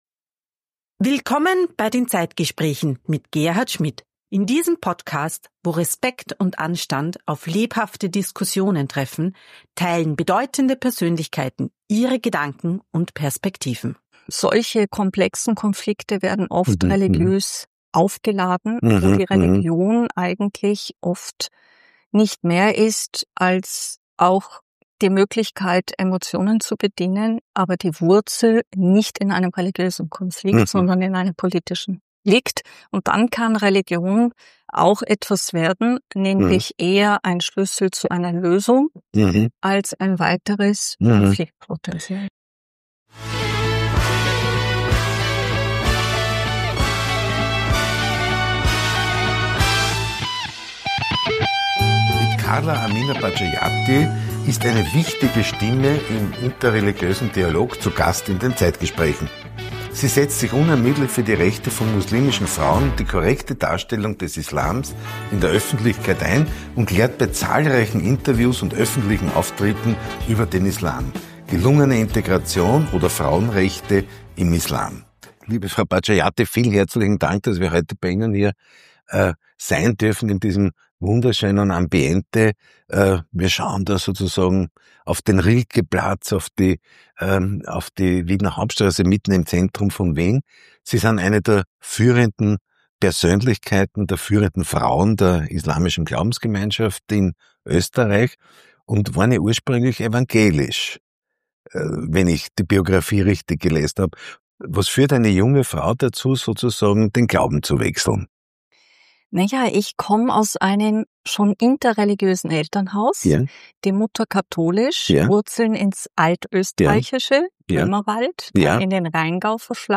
Das Gespräch suchen und finden.